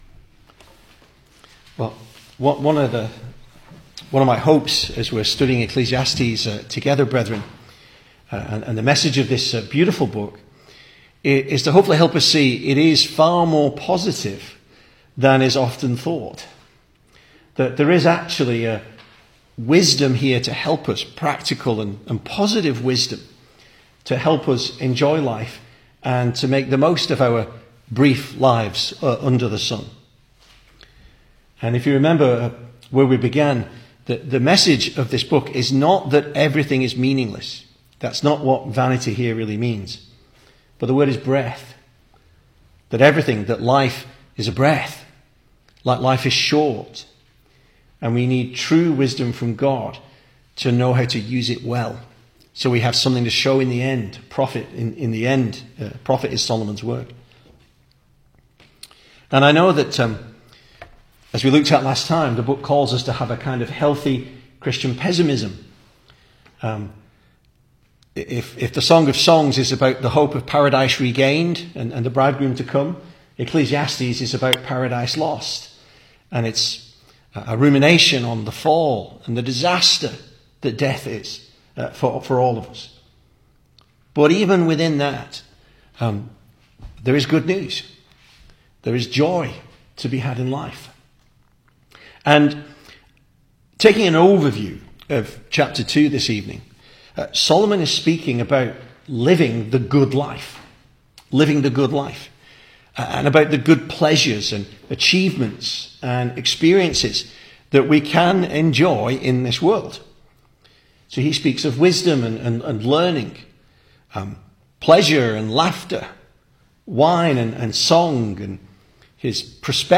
2025 Service Type: Weekday Evening Speaker